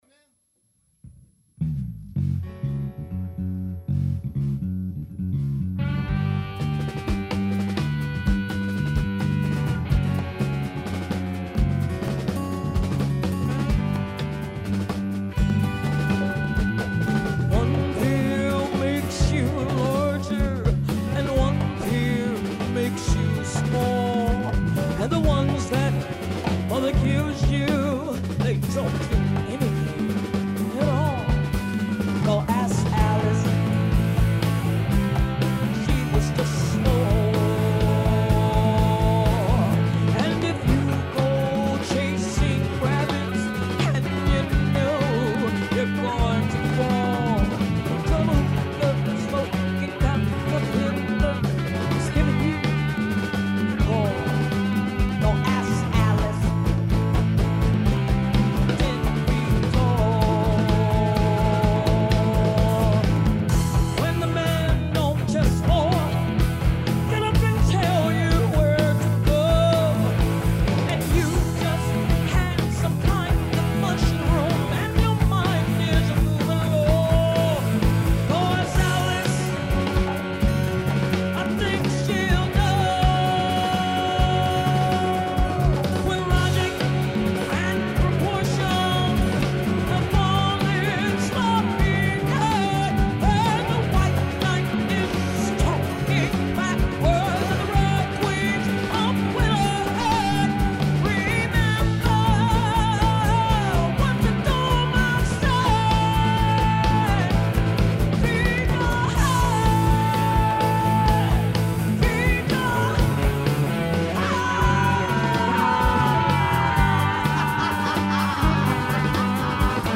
Michigan Ave & Brooklyn in front of PJ’s Lager House